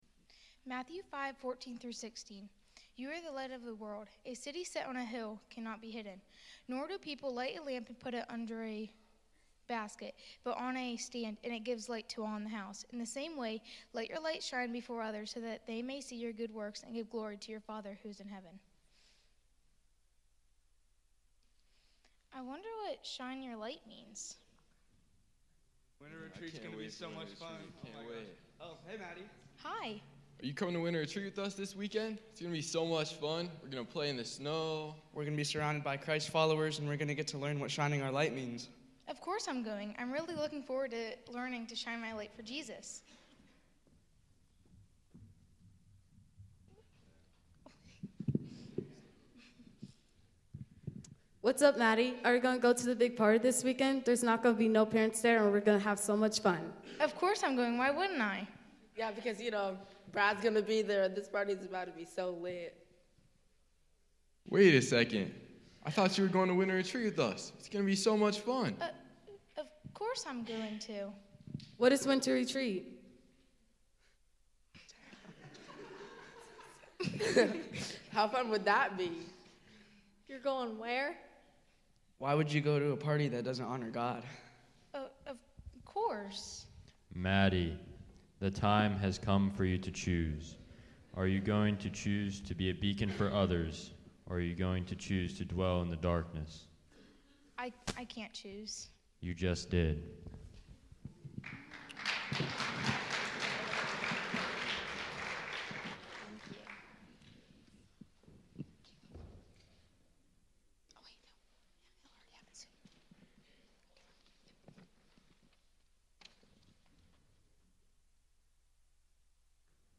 2026 Winter Retreat Youth Service